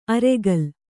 ♪ aregal